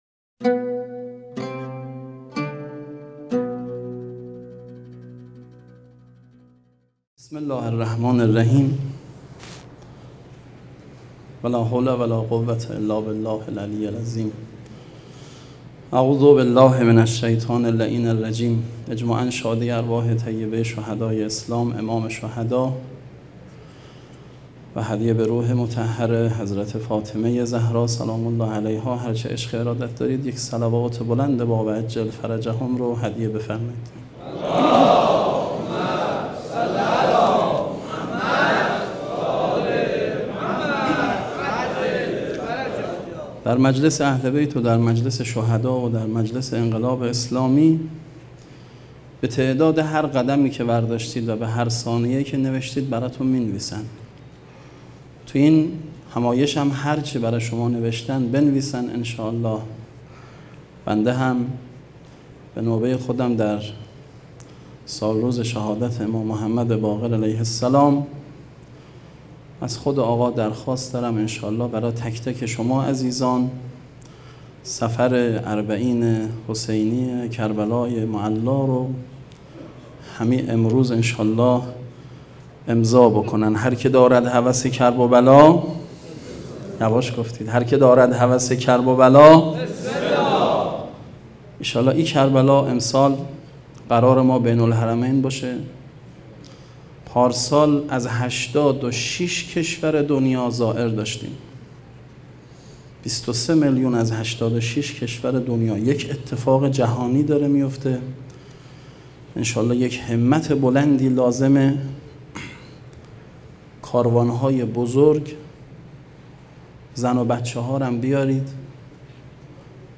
سخنرانی
ششمین همایش هیأت‌های محوری و برگزیده کشور | شهر مقدس قم - مجتمع یاوران مهدی (عج)